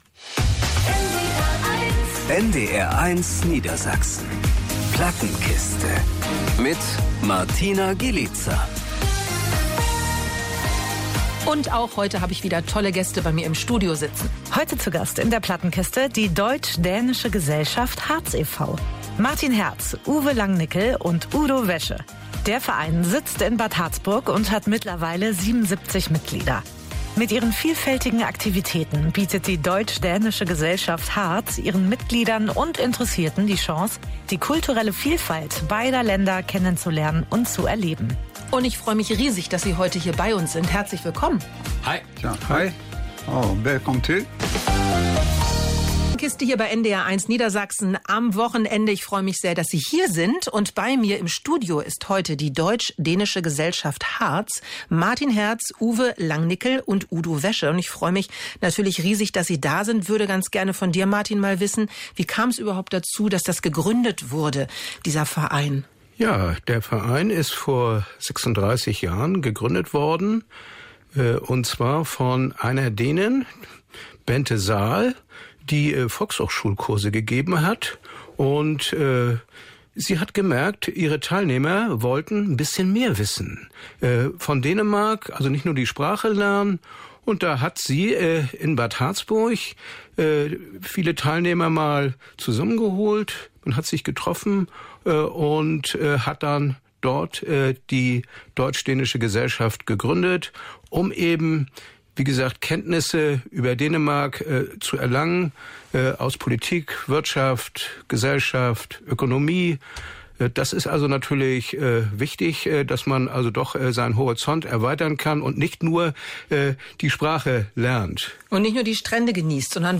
🎤 Mitschnitt der Interviews